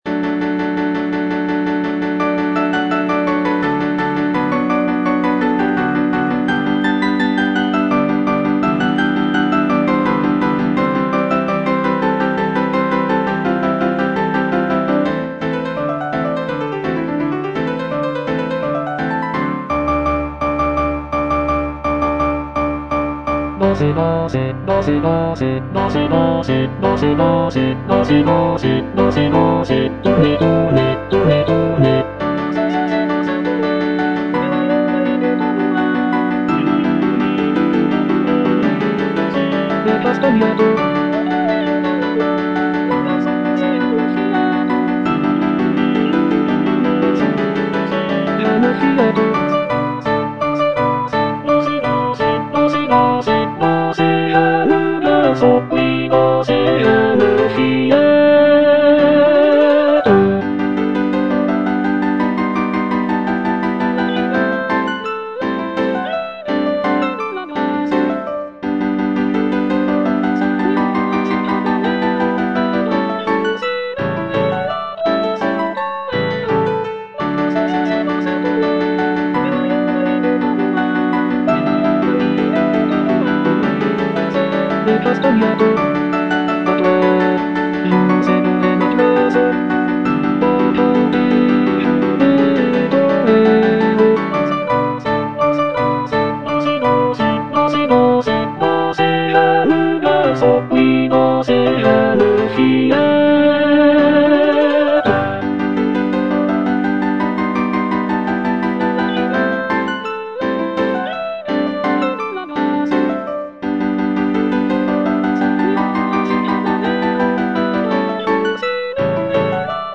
G. BIZET - CHOIRS FROM "CARMEN" Dansez, dansez (bass I) (Emphasised voice and other voices) Ads stop: auto-stop